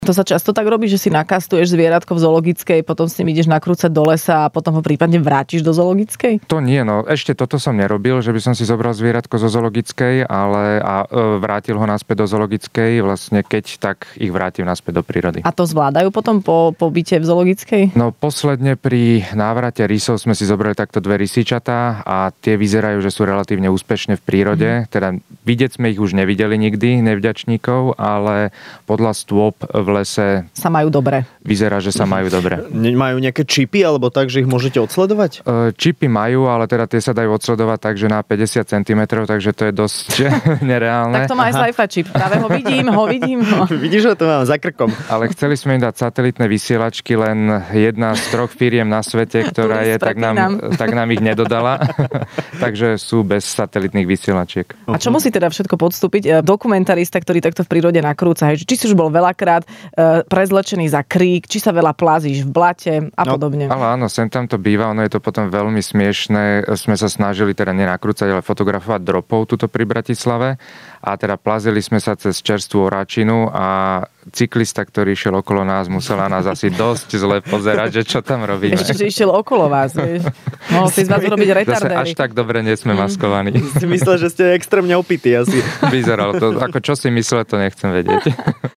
Dokumentarista v Rannej šou | Fun rádio